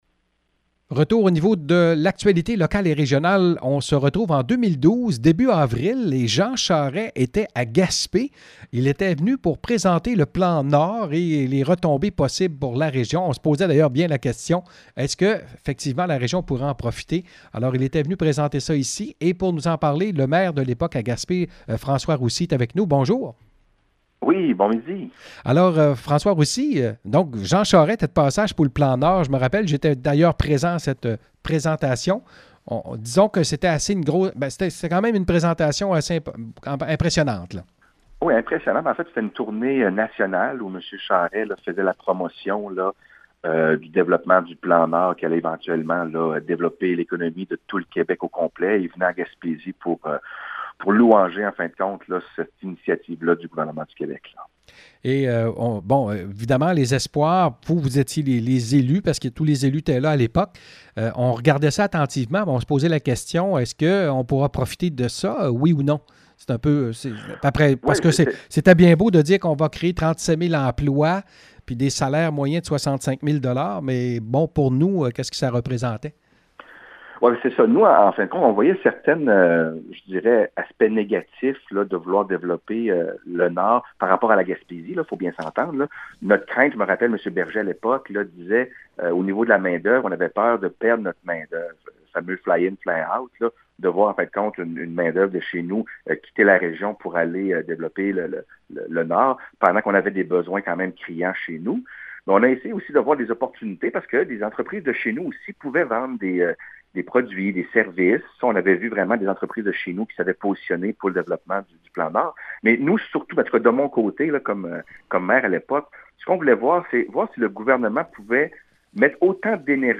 En 2012, le premier ministre Jean Charest, était à Gaspé pour annoncer le Plan Nord. Entrevue avec le maire de Gaspé de l’époque, François Roussy: